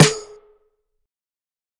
描述：我很喜欢手鼓，所以我设计了一些鼓声，并加入了一些手鼓。
Tag: 打击乐 TamboRock